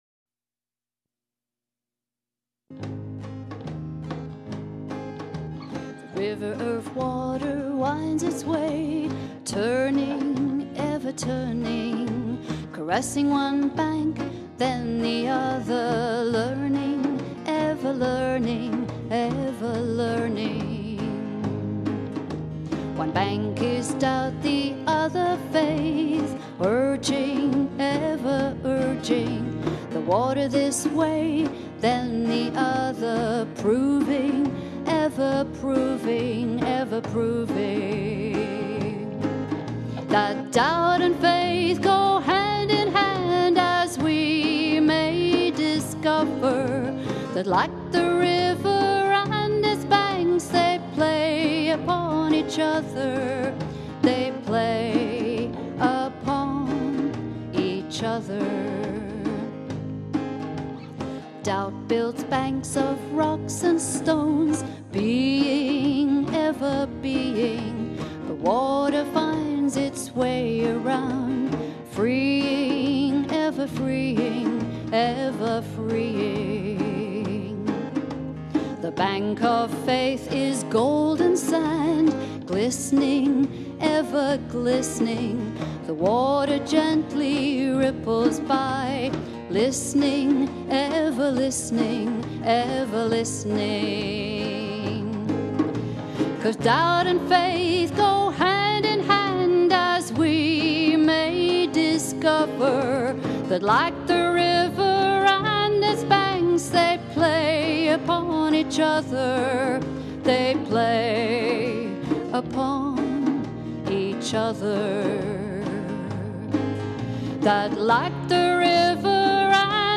Bass
Percussion
Recorded "Live"
It was like recording in the intimacy of a living room.